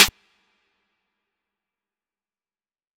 Snares
Snare (beibs in the trap) (1).wav